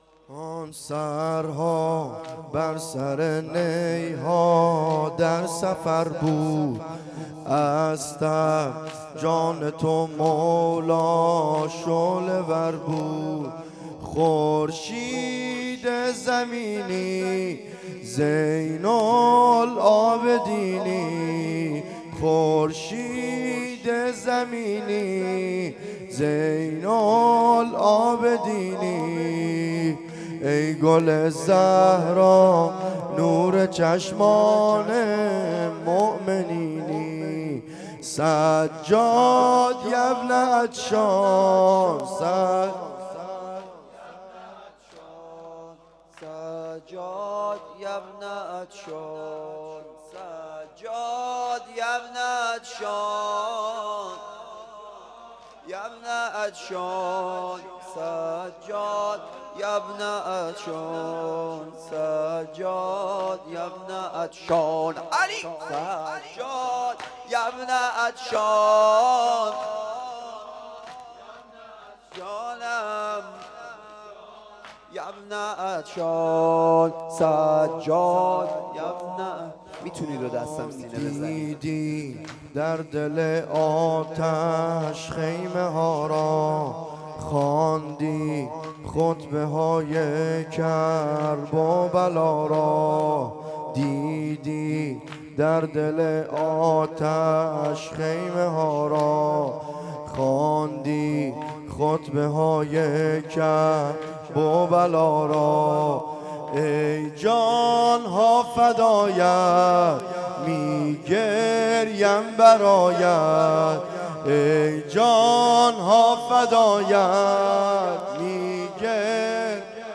شب دوازدهم ماه محرم